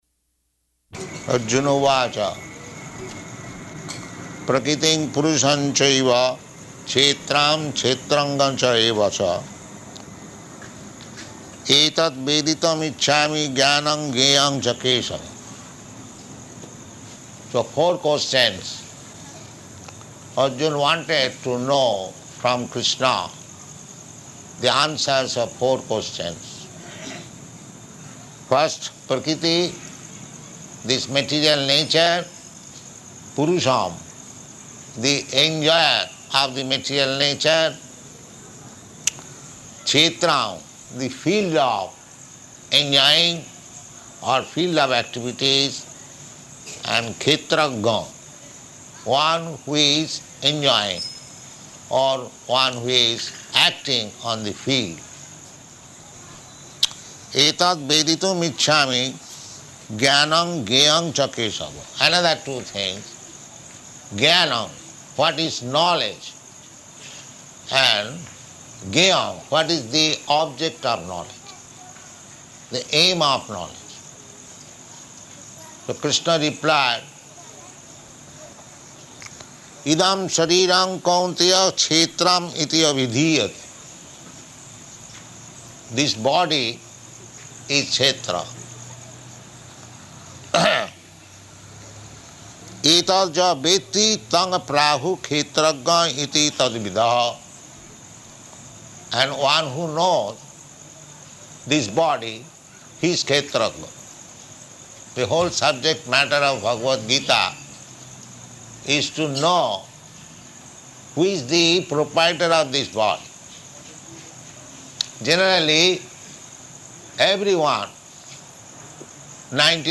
Location: Bombay